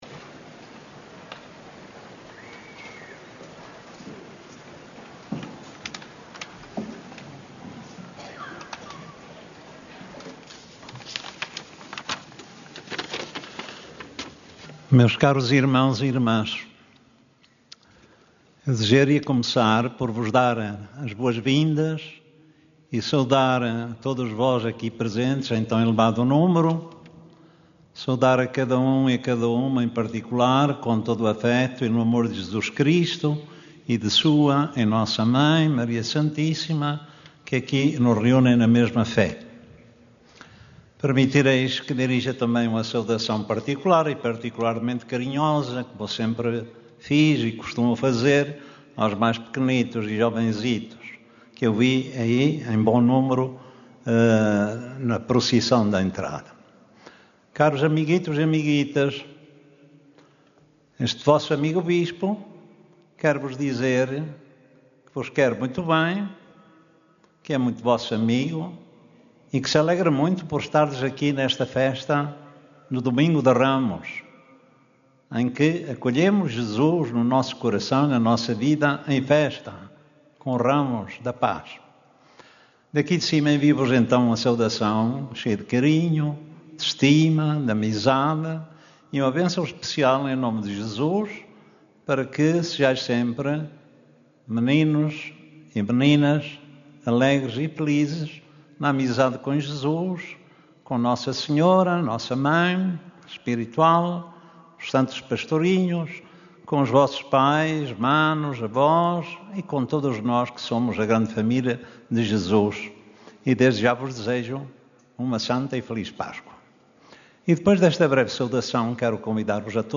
Mais de 30 mil peregrinos participaram na missa de Domingo de Ramos, no Recinto de Oração do Santuário de Fátima, presidida pelo cardeal D. António Marto.
Na homilia que proferiu, o bispo emérito de Leiria-Fátima destacou dois momentos distintos: a entrada de Jesus em Jerusalém e a narração da Paixão do Senhor.
homiliadantoniomarto.mp3